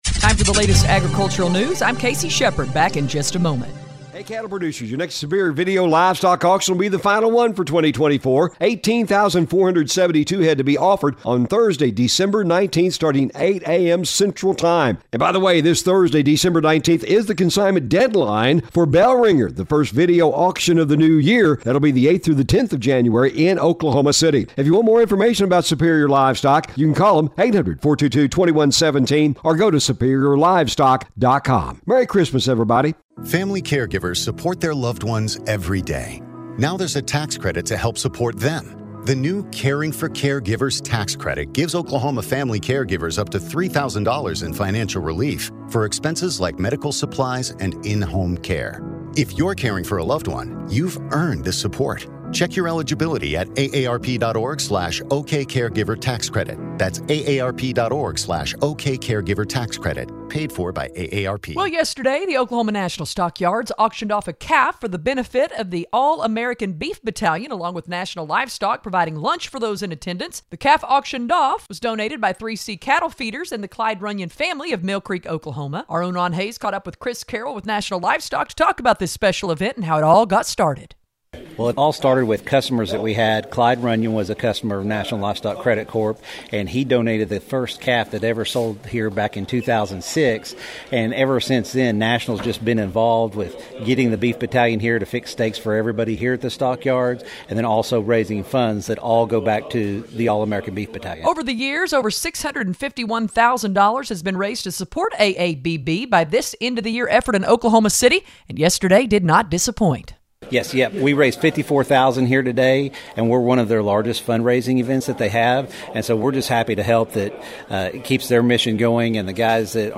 We invite you to listen to us on great radio stations across the region on the Radio Oklahoma Ag Network weekdays-